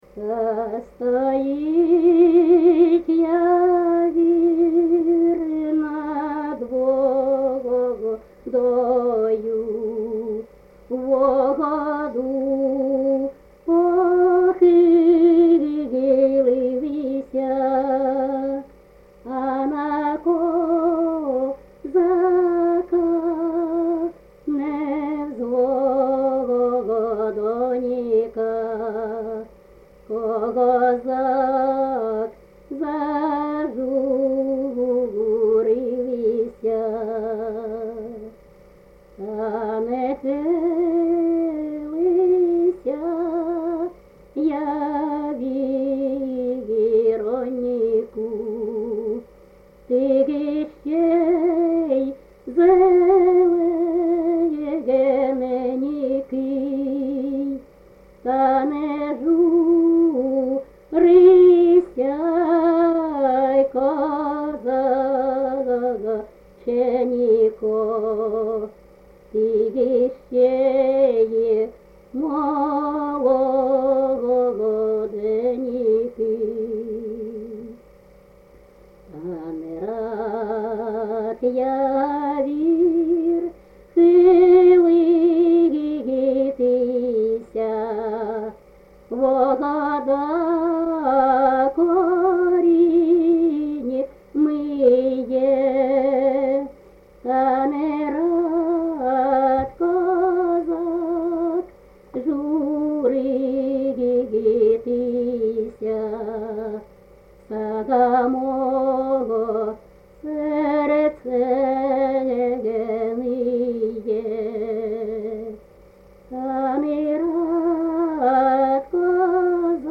ЖанрКозацькі, Пісні літературного походження
Місце записус-ще Михайлівське, Сумський район, Сумська обл., Україна, Слобожанщина